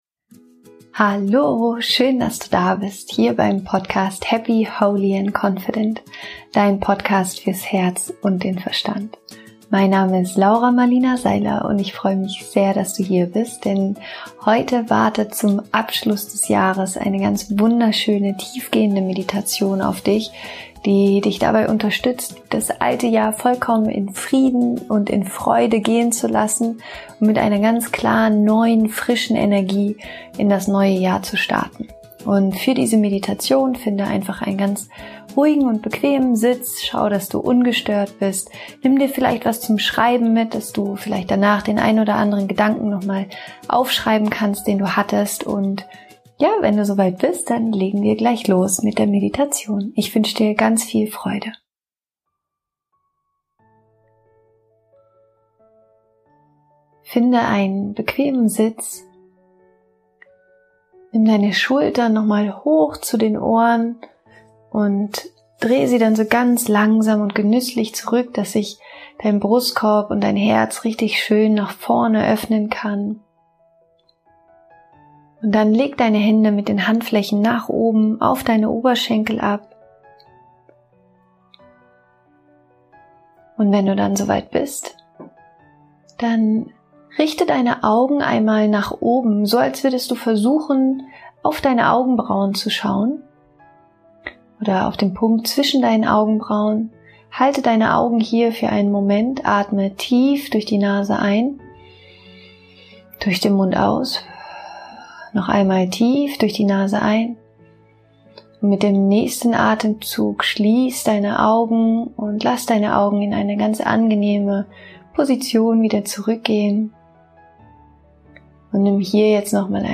Meditation um erfüllt und entspannt ins neue Jahr zu starten
Meditation um erfüllt und entspannt ins neue Jahr zu starten happy, holy & confident® Dein Podcast fürs Herz und den Verstand Download Heute gibt’s im Podcast eine wunderschöne und kraftvolle Meditation, um das Jahr voller Freude, Dankbarkeit und Liebe abzuschließen und das nächste Jahr willkommen zu heißen. Nimm dir die Zeit, um den Blick nach Innen zu richten, Altes loszulassen und dich mit neuer, frischer Energie für das neue Jahr auszurichten.